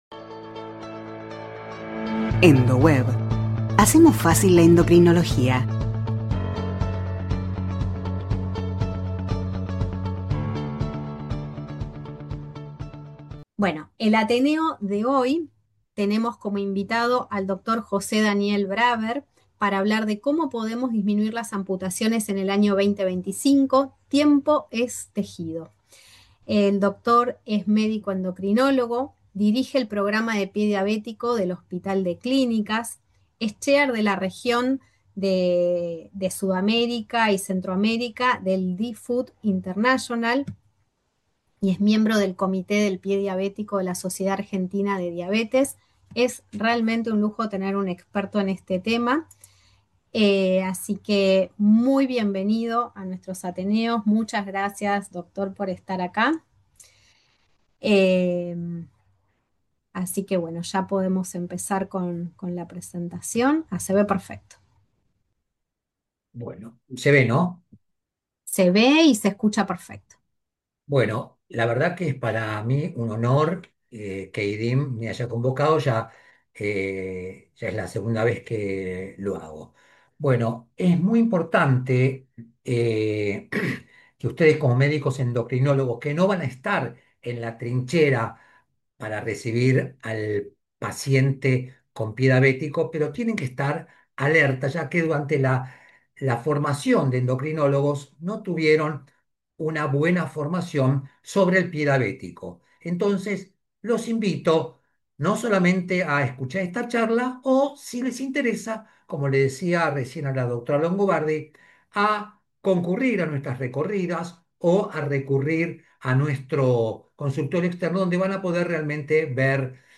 Ateneos médicos
La reunión se centró en destacar la investigación ósea latinoamericana y su representación en publicaciones científicas internacionales, con presentaciones que mostraban las contribuciones y desafíos de la región en el campo de la osteología. Los participantes discutieron la necesidad de aumentar la colaboración y visibilidad de los investigadores latinoamericanos en las comunidades científicas globales, abordando temas como la limitada representación en los consejos editoriales y la importancia de fomentar la investigación entre los estudiantes.